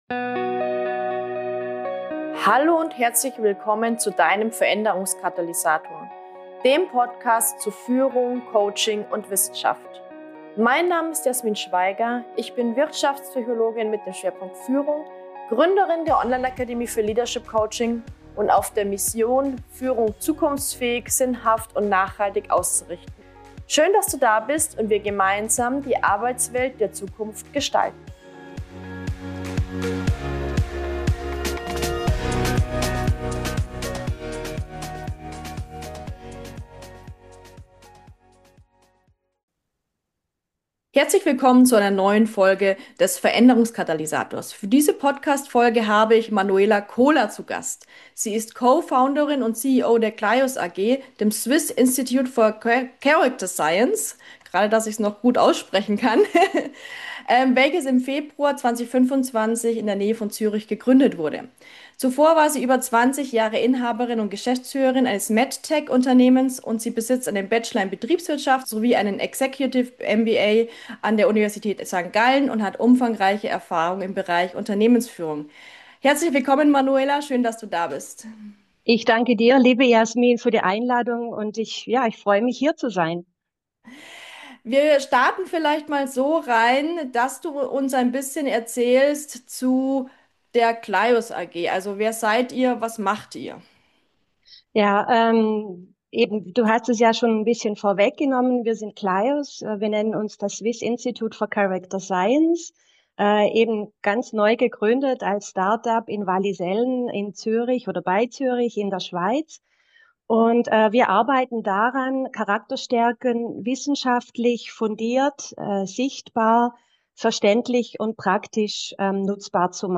Der wissenschaftlich fundierte Stärkentest CLYOScope - Interview